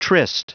Prononciation du mot tryst en anglais (fichier audio)
Prononciation du mot : tryst